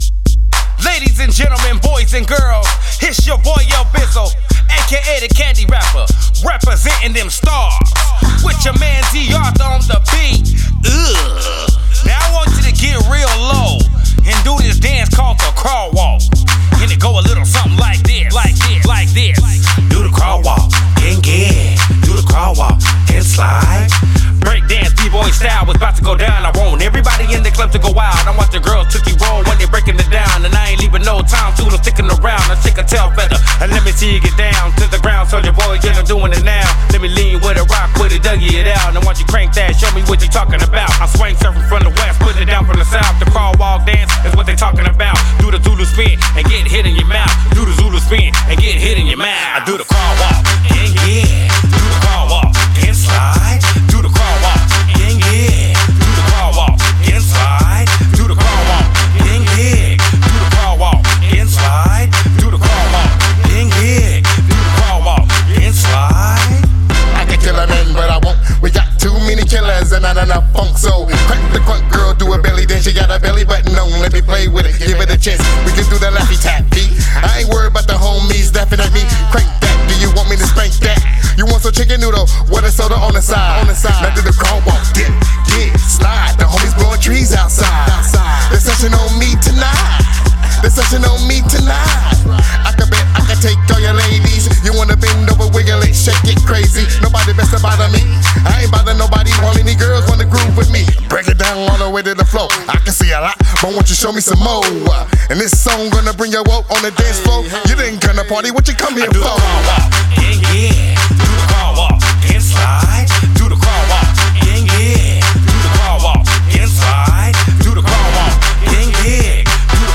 Hiphop
seven man hop-funk group